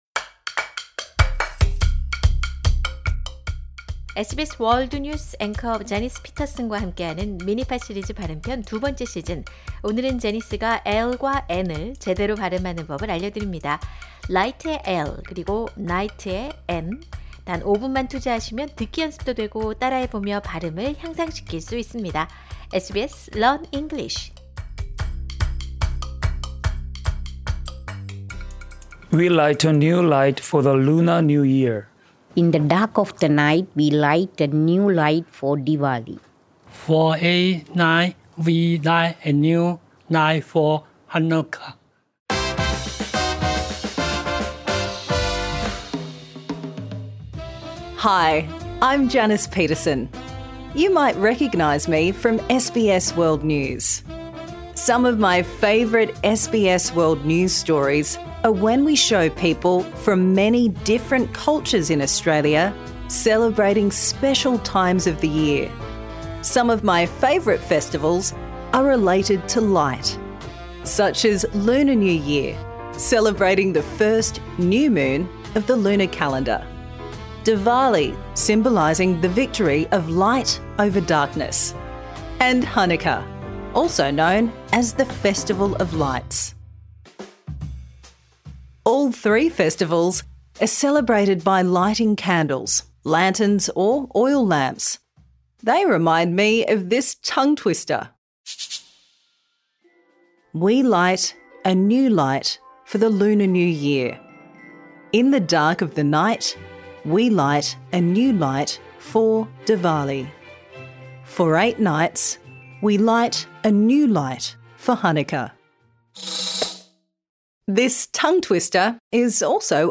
Minimal Pairs: /l/ light, low, line, lot, lap, thrill /n/ night, no, nine, not, nap, train.
This lesson suits all learners at all levels.